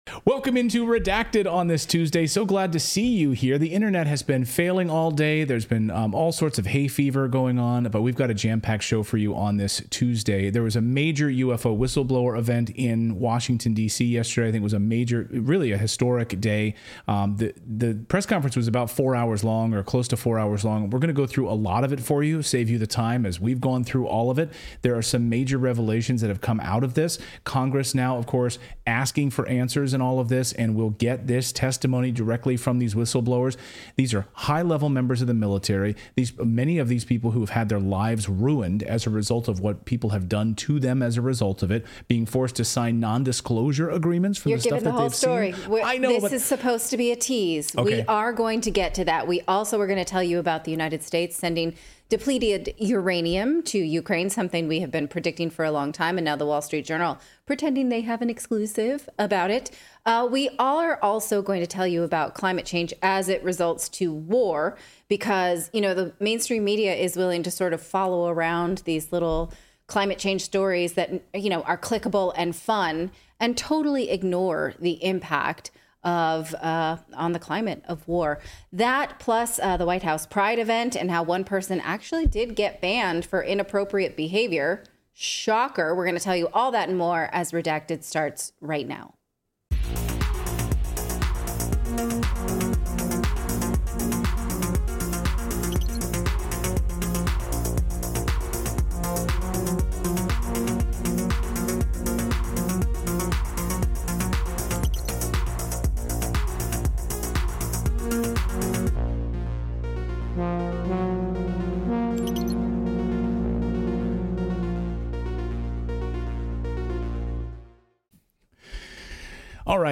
Redacted host Clayton Morris walks us through the highlights of the UFO event.